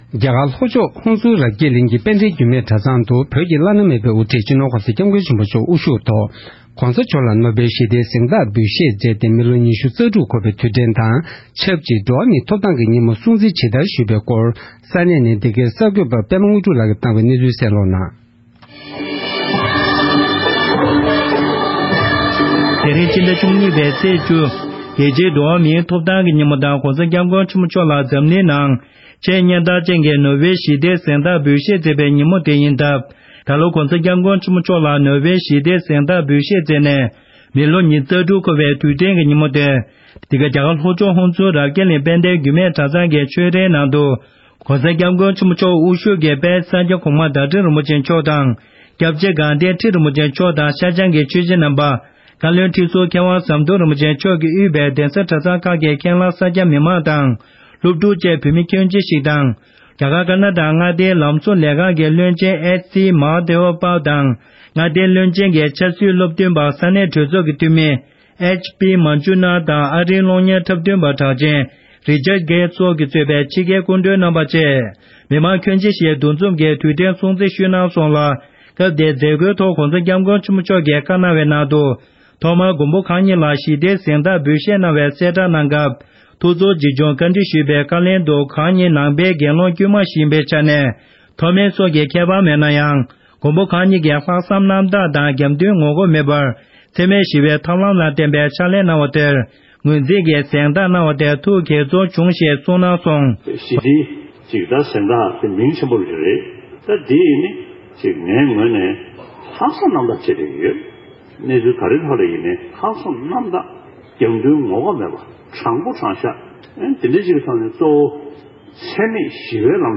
ནོ་སྦེལ་ཞི་བདེའི་གཟེངས་རྟགས་ཕུལ་ནས་ལོ་༢༦འཁོར་བ། རྒྱ་གར་ལྷོ་ཕྱོགས་དཔལ་ལྡན་བརྒྱུད་སྨད་གྲྭ་ཚང་དུ་༸གོང་ས་མཆོག་ལ་ནོ་སྦེལ་ཞི་བདེའི་གཟེངས་རྟགས་ཕུལ་ནས་ལོ་༢༦འཁོར་བའི་ཉིན་མོར་སྲུང་བརྩི་ཞུས་པ།
སྒྲ་ལྡན་གསར་འགྱུར། སྒྲ་ཕབ་ལེན།